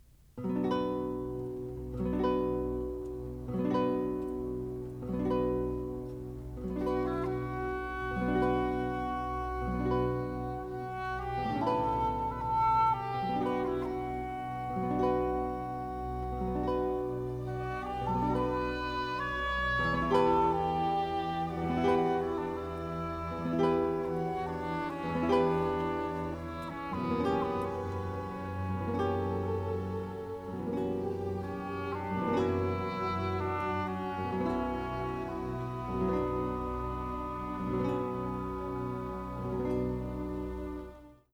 Sarasota Orchestra's mission is to engage, educate, and enrich our community through high-quality, live musical experiences.
In the Fifth Symphony, the power is purely instrumental, with no underlying text or story providing context.